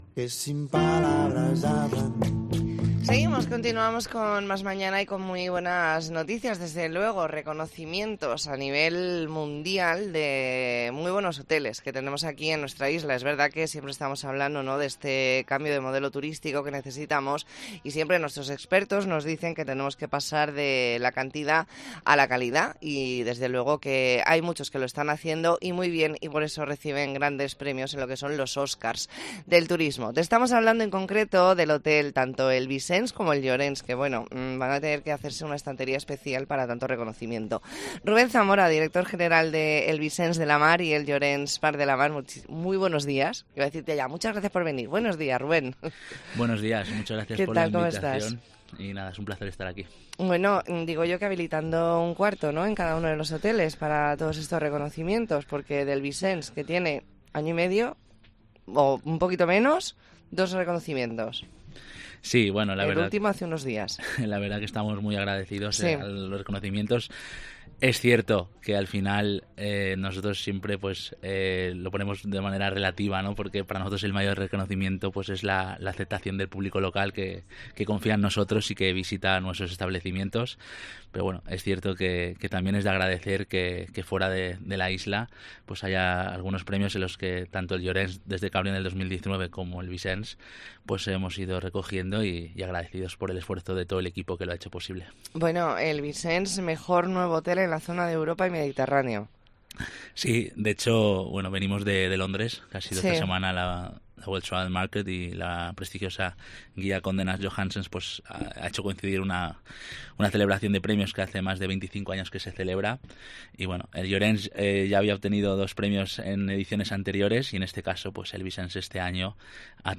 ntrevista en La Mañana en COPE Más Mallorca, viernes 10 de noviembre de 2023.